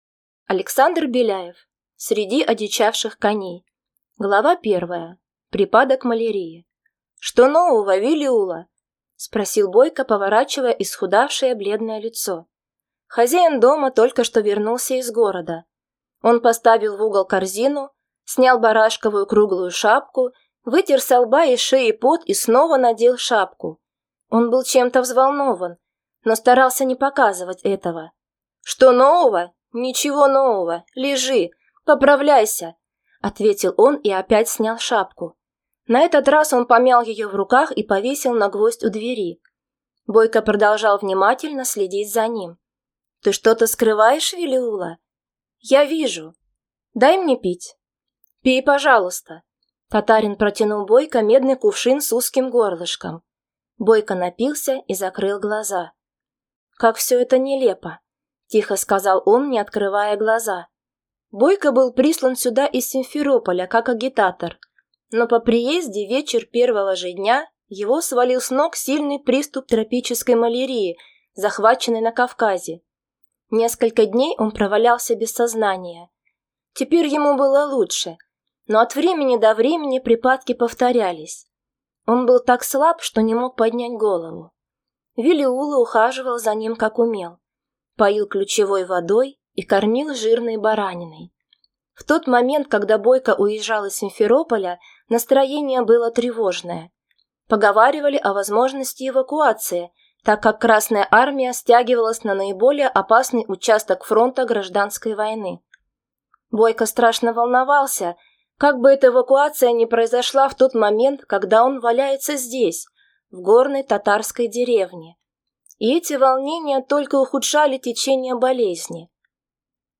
Аудиокнига Среди одичавших коней | Библиотека аудиокниг